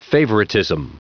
Prononciation du mot favoritism en anglais (fichier audio)
Prononciation du mot : favoritism